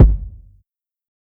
TC2 Kicks3.wav